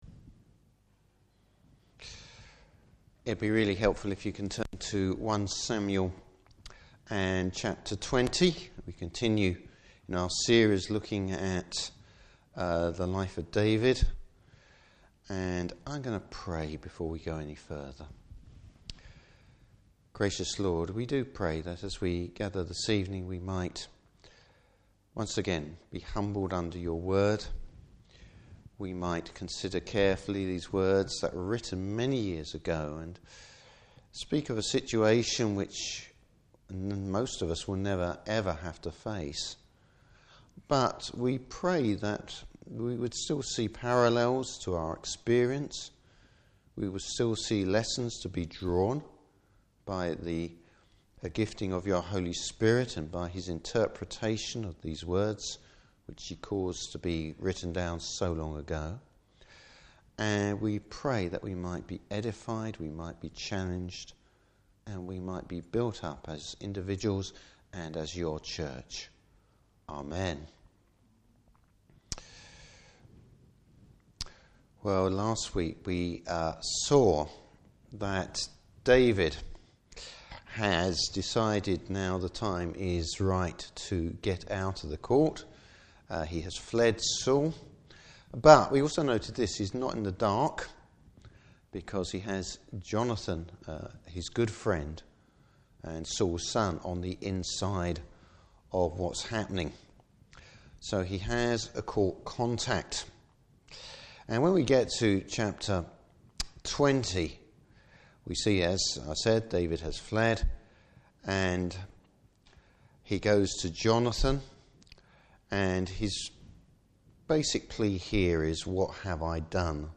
Service Type: Evening Service David’s fellowship with Jonathan.